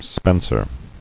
[spen·cer]